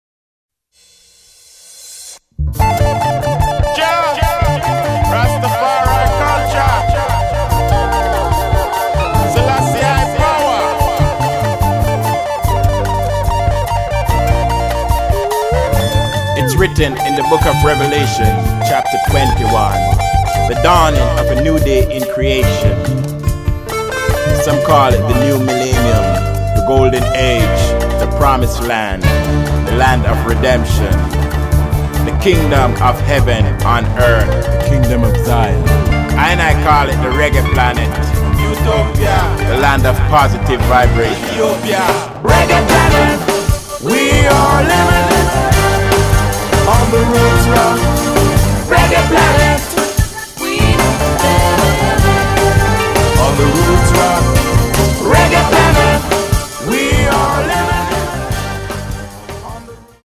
Genre: Reggae/World Reggae/Roots